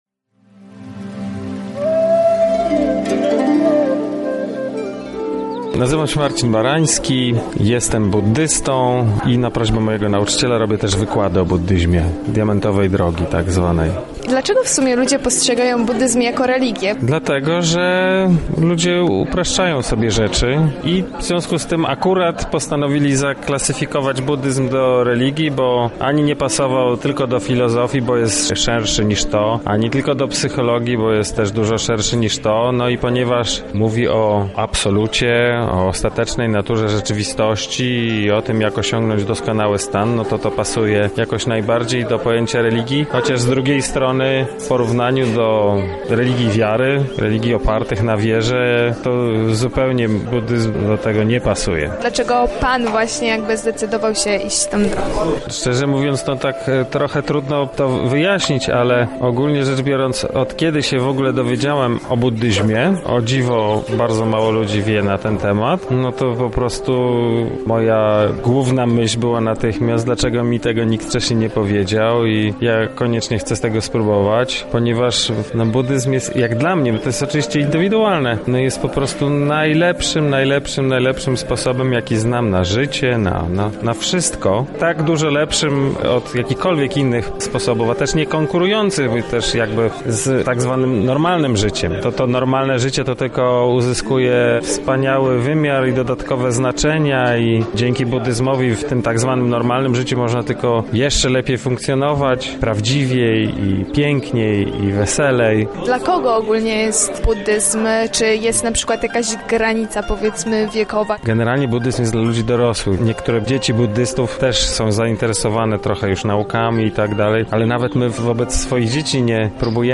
W Lublinie wystartował Festiwal Kultury Buddyjskiej ,,Przestrzeń umysłu”.